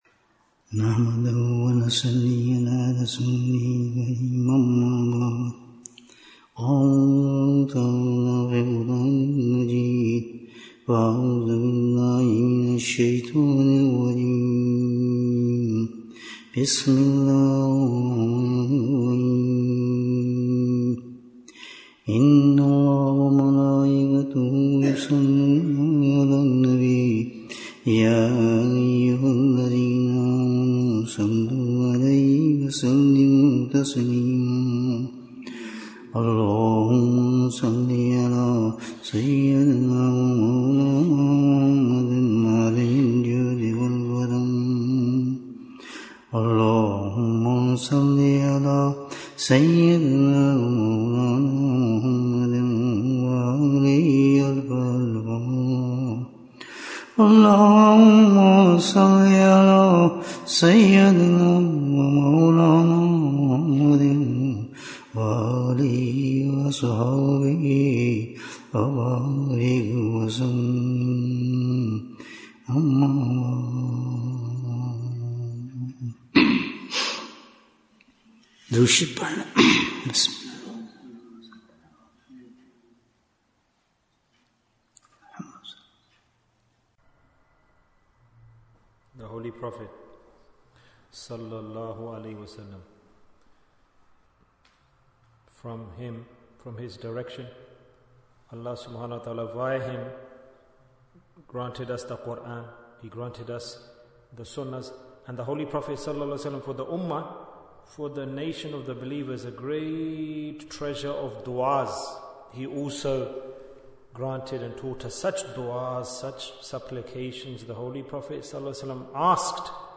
What do we get from Allah Waley? Bayan, 50 minutes25th July, 2024